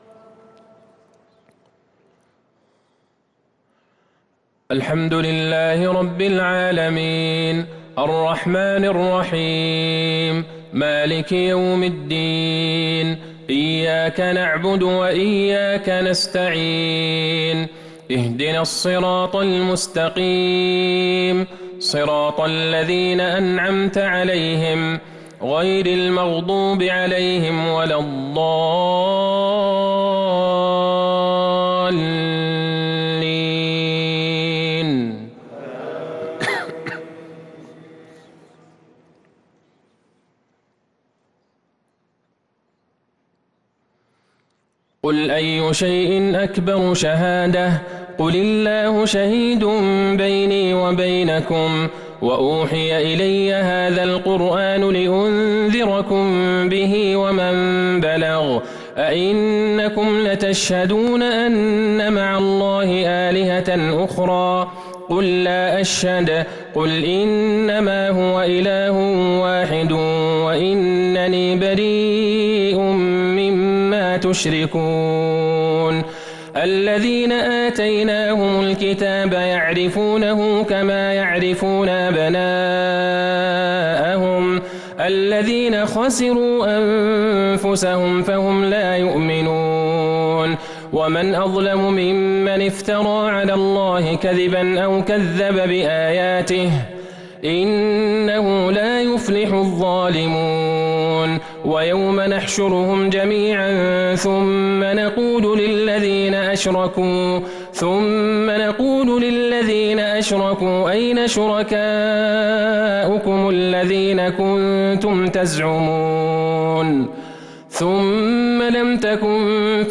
عشاء الاثنين ٤ شعبان ١٤٤٣هـ | سورة الأنعام ١٩ - ٣٥ | Isha prayer from Surah Al-An’aam 7-3-2022 > 1443 🕌 > الفروض - تلاوات الحرمين